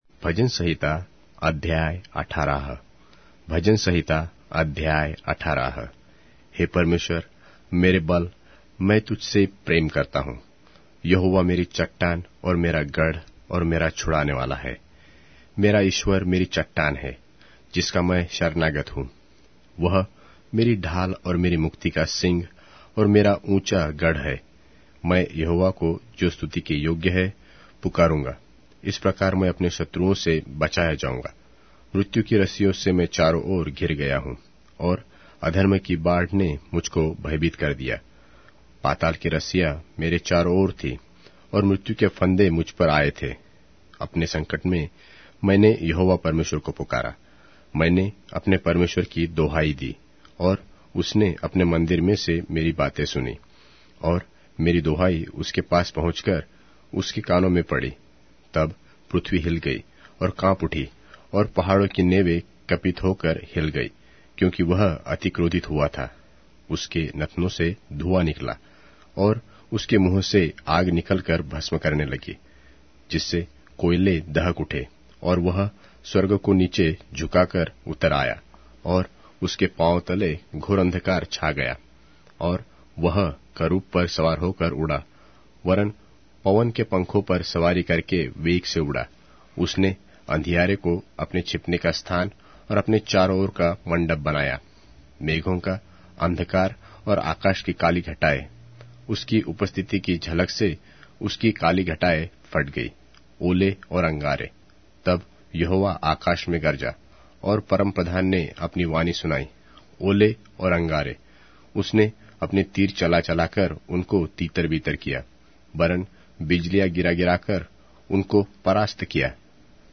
Hindi Audio Bible - Psalms 48 in Lxxrp bible version